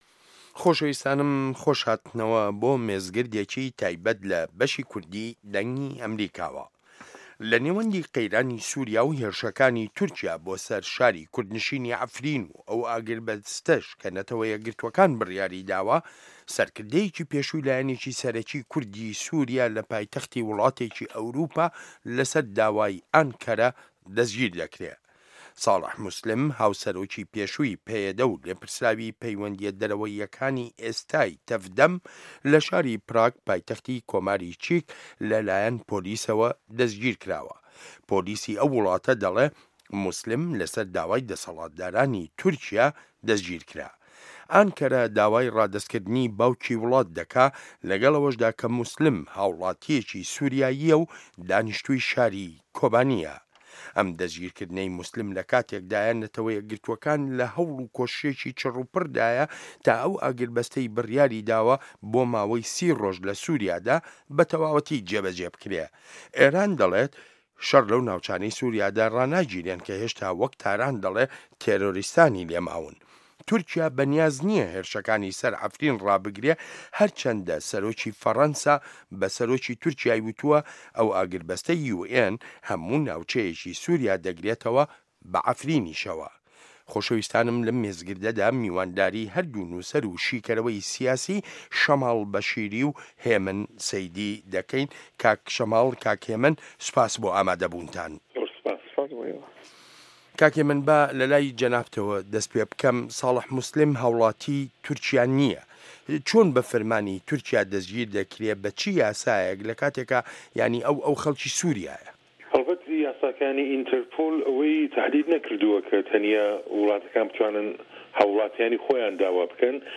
مێزگرد: دەستگیرکردنی ساڵح موسلم